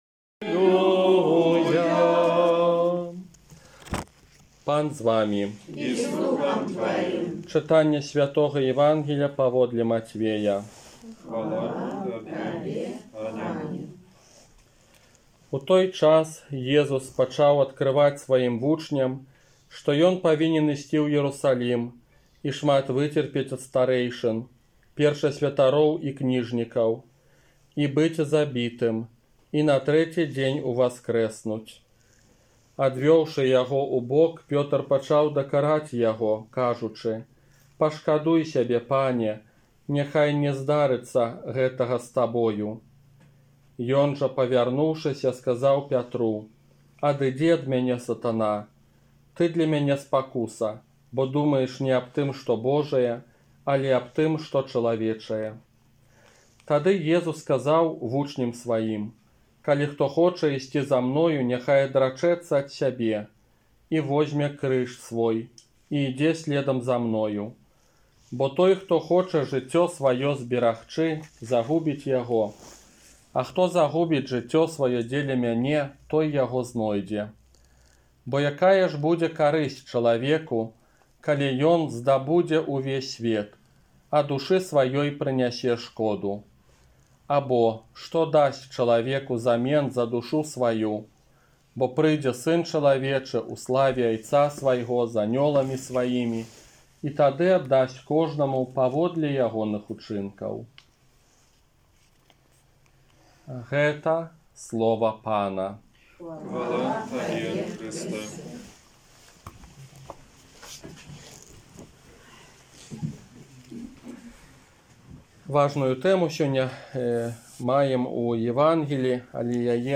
ОРША - ПАРАФІЯ СВЯТОГА ЯЗЭПА
Казанне на дваццаць другую звычайную нядзелю